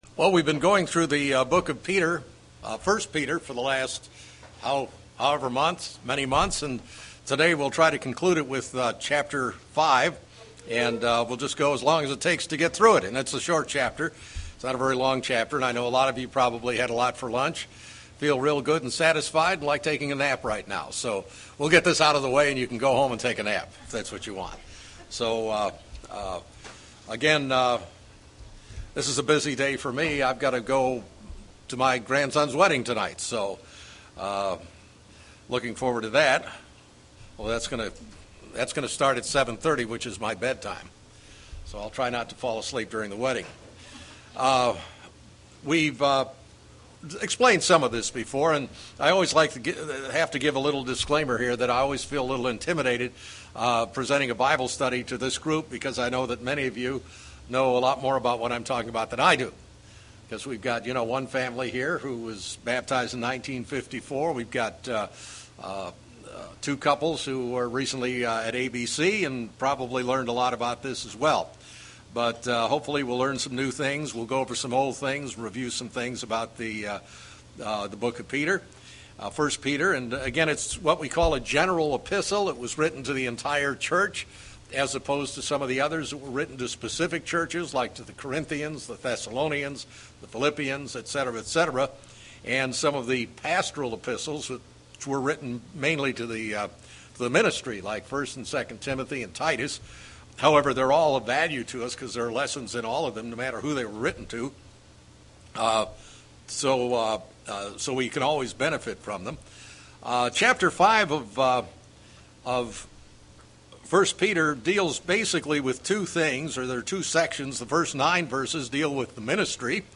A verse by verse Bible study through chapter 5 of 1 Peter.
Given in Springfield, MO
UCG Sermon Studying the bible?